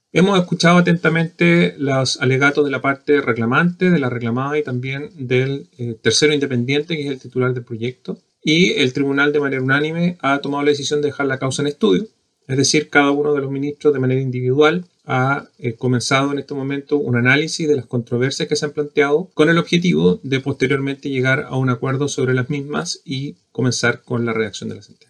El ministro y presidente subrogante del Tercer Tribunal Ambiental, Iván Hunter, se refirió a los alegatos en la reclamación interpuesta por las juntas de vecinos Membrillar y Los Nogales de la misma localidad, por el proyecto de Biodiversa.